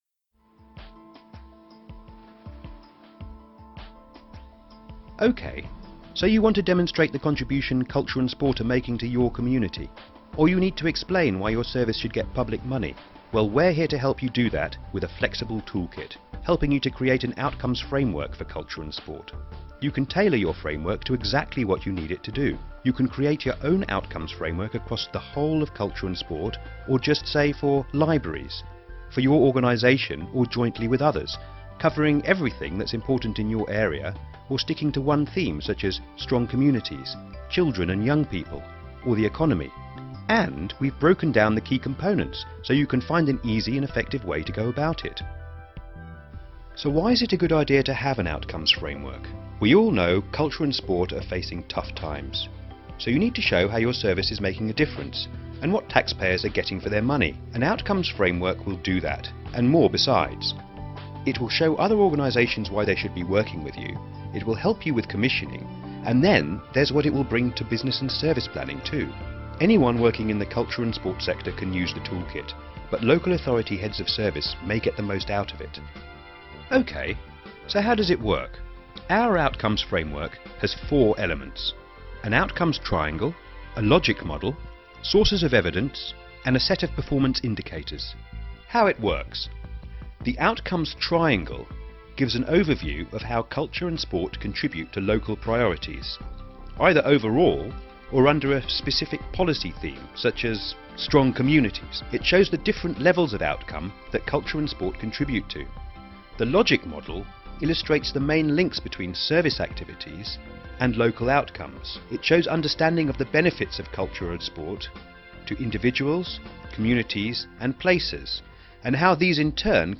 Rounded, warm, confident, smooth, informed, distinctive and slightly RP.
britisch
Sprechprobe: Industrie (Muttersprache):